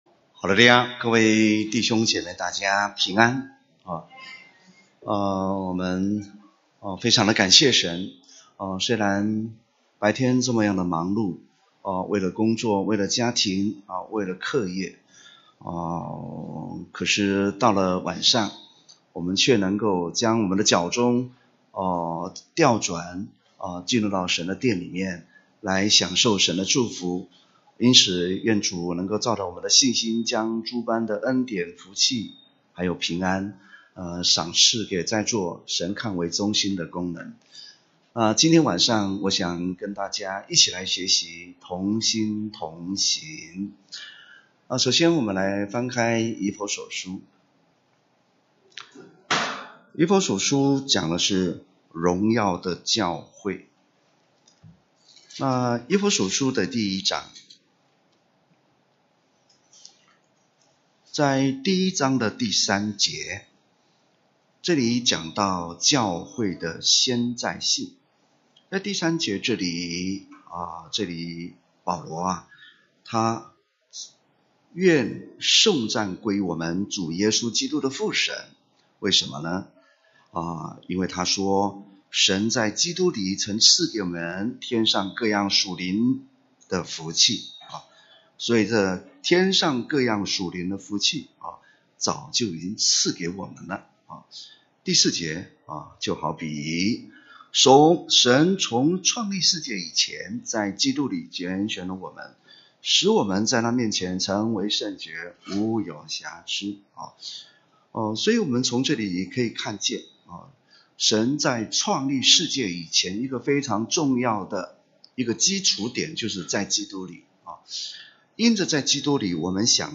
2018年1月份講道錄音已全部上線